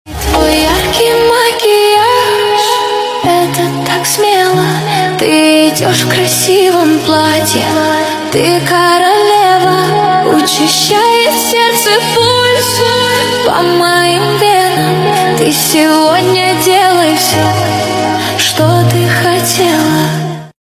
поп нейросеть